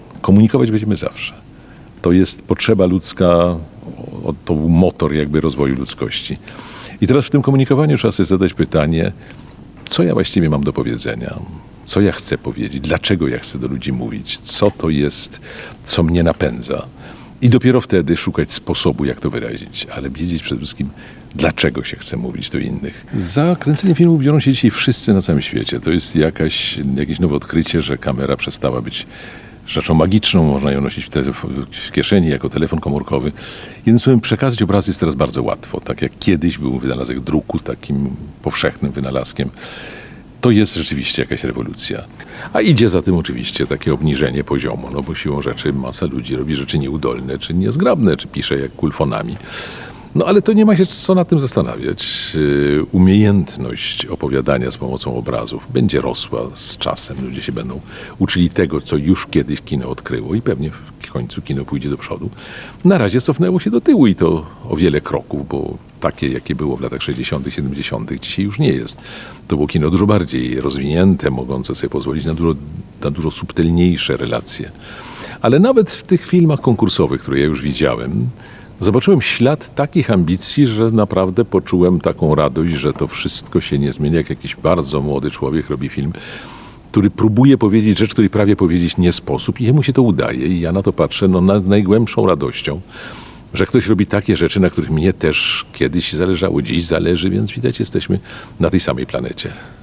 W sobotę (28.07) w Suwalskim Ośrodku Kultury wszyscy chętni mogli obejrzeć film pod tytułem „Cwał” w reżyserii Krzysztofa Zanussiego, jednego z najwybitniejszych polskich reżyserów i scenarzystów. Mistrz po zakończonym pokazie wprowadził wszystkich zgromadzonych w tajniki pracy reżysera.